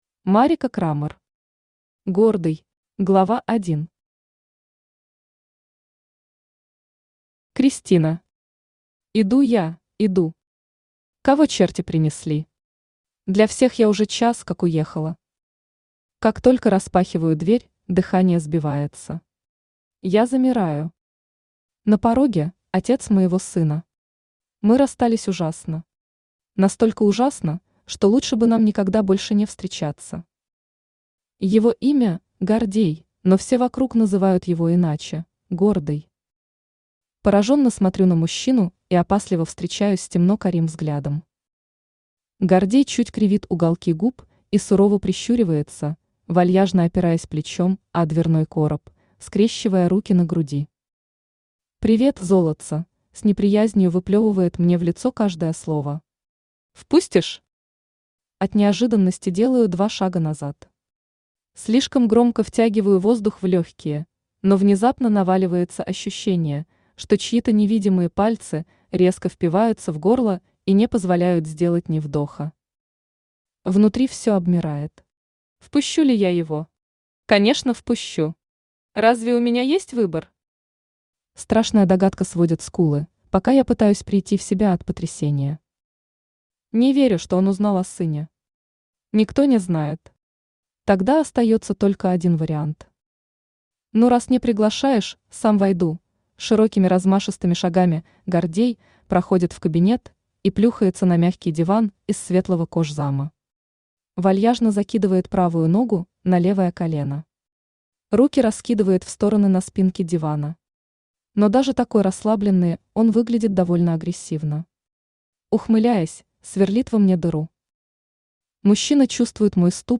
Aудиокнига Гордый Автор Марика Крамор Читает аудиокнигу Авточтец ЛитРес.